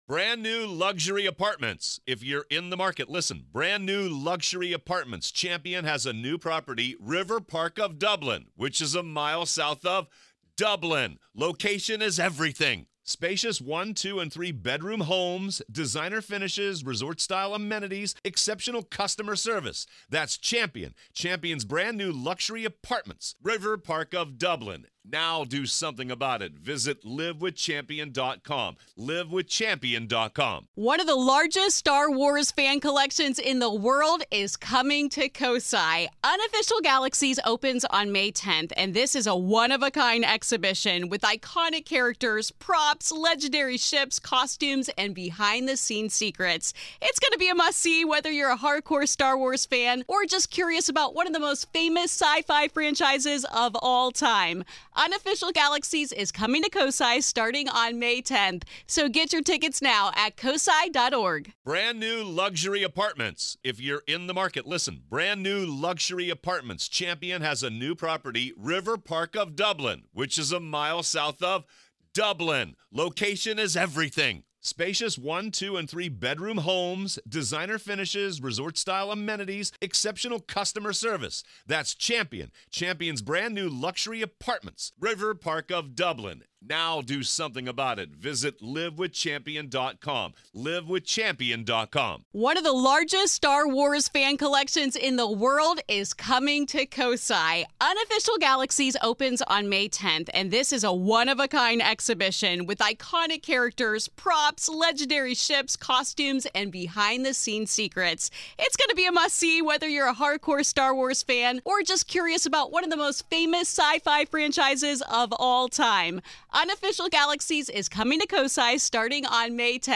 In this episode of The Grave Talks , we delve deep into the haunted history of the Deane House, exploring its dark past, the spirits that may still linger, and the chilling question: What do the ghosts of the Deane House want? This is Part Two of our conversation.